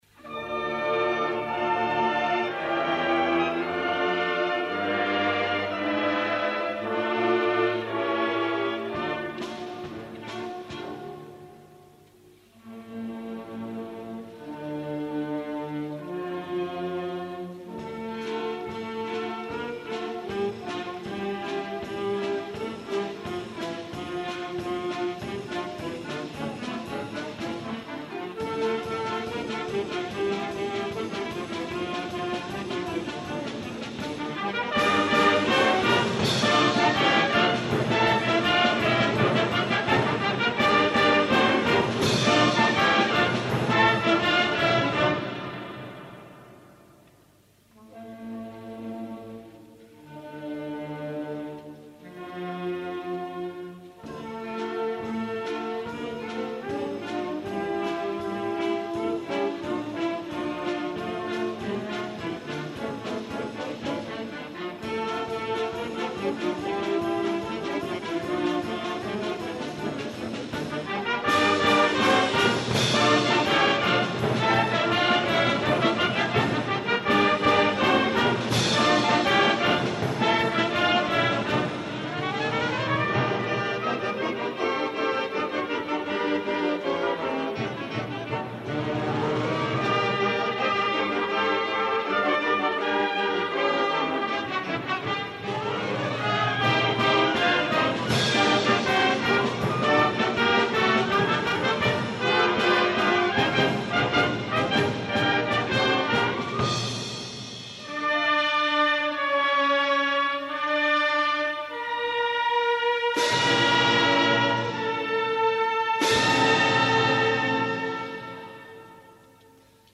Concert sa fira 1988. Esglesia parroquial de Porreres Nostra Senyora de la Consolació.